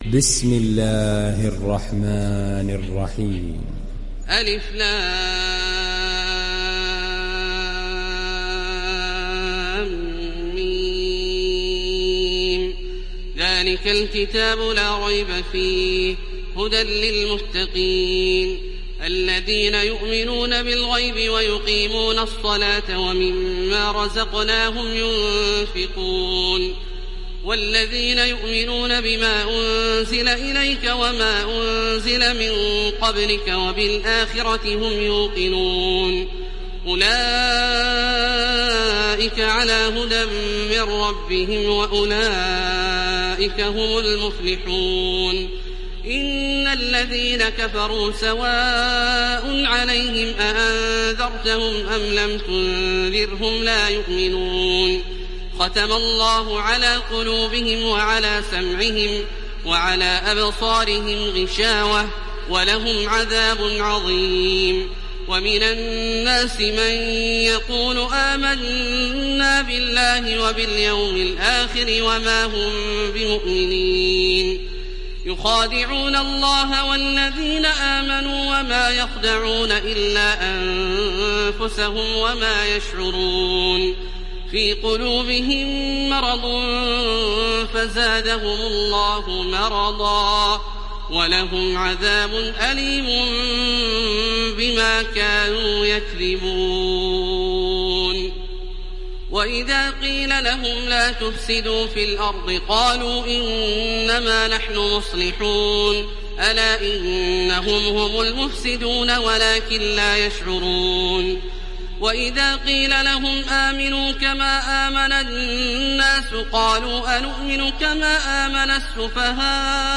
Sourate Al Baqara Télécharger mp3 Taraweeh Makkah 1430 Riwayat Hafs an Assim, Téléchargez le Coran et écoutez les liens directs complets mp3
Télécharger Sourate Al Baqara Taraweeh Makkah 1430